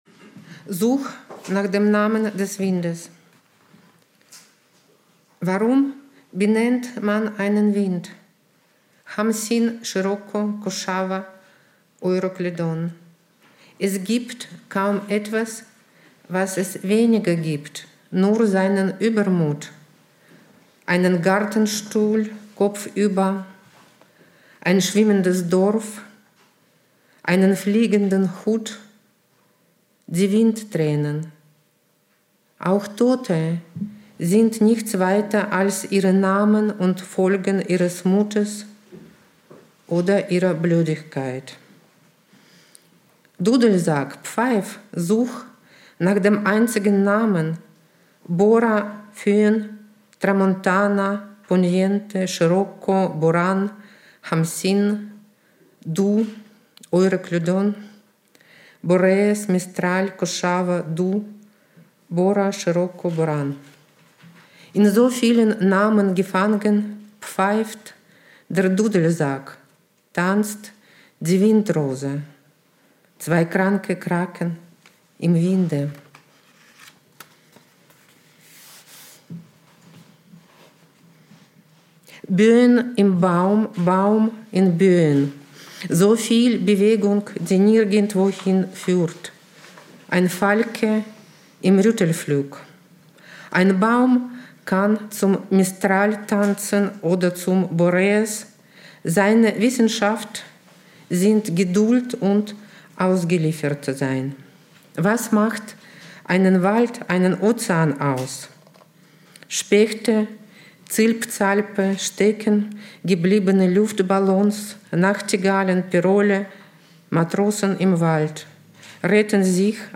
Olga Martynova liest aus "Such nach dem Namen des Windes"